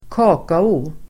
Ladda ner uttalet
Uttal: [²k'a:kao]